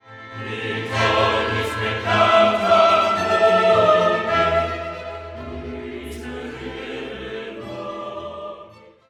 The pace continues in brisk and bracing duple-time as Mozart crams the entire Gloria into a space only about thirty seconds longer than the Kyrie.  There are no pauses and the pace never slows.  No puzzles here; the performers must sing fast and articulate clearly against a frenetic and insistent orchestral background.
Qui tollis“, chorus; “Quoniam“, chorus; and “Cum Sancto Spiritu“, chorus.